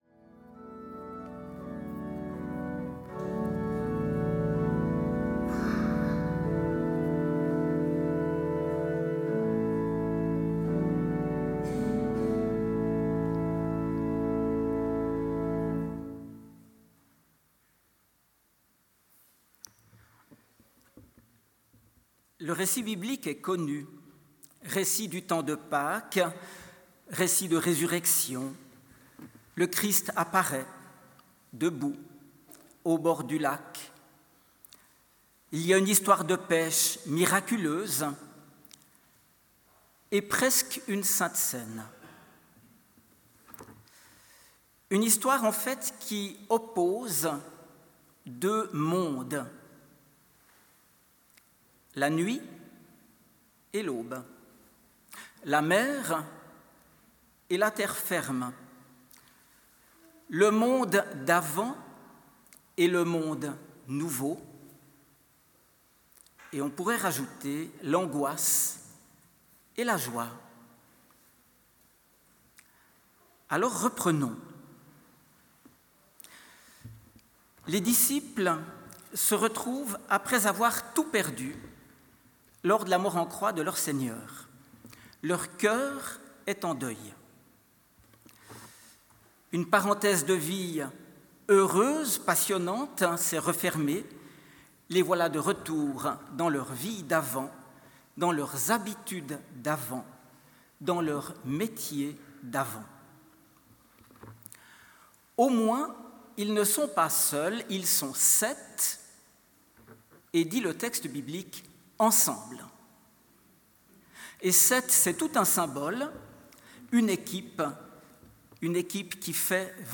Ce dimanche, la paroisse réformée de La Neuveville a vécu un moment à la fois beau, fort, simple et profondément important : le culte d’installation de trois nouveaux collègues, pasteurs et animateurs, dans une atmosphère empreinte de joie, de chaleur humaine et de spiritualité.
La célébration a été rythmée par de magnifiques prestations musicales : les organistes ont su toucher les cœurs avec une justesse et une beauté remarquables, tandis qu’un accordéoniste a apporté une note latino-joyeuse qui a réjoui l’assemblée. Ce mélange de solennité et de légèreté a contribué à faire de ce culte un moment inoubliable.